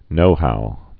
(nōhou)